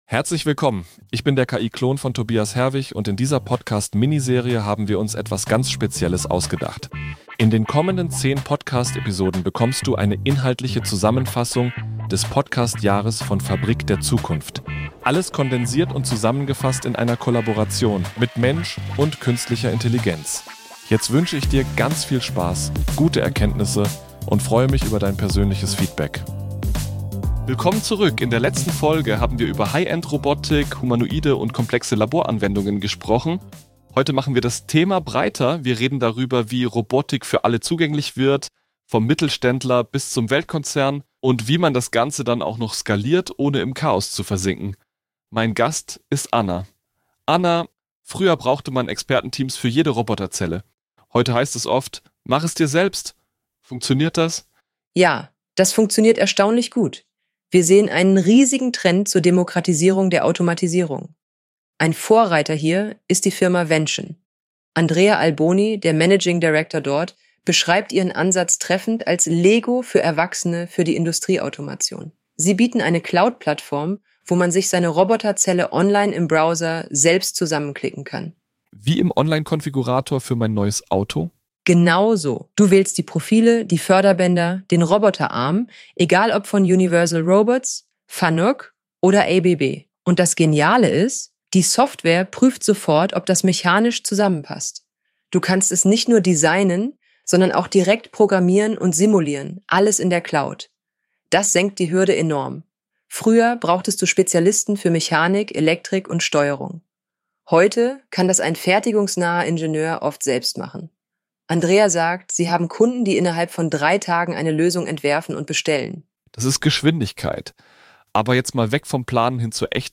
Expertin für agile Automation
KI‑Klon